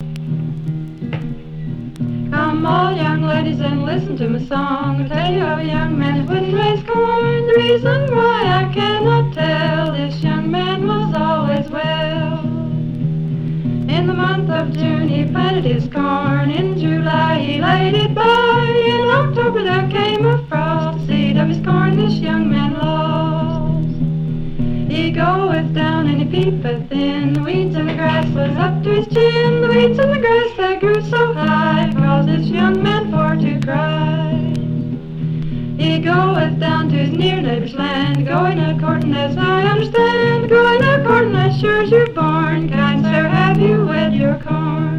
Folk, World, Apparachian Music　USA　12inchレコード　33rpm　Stereo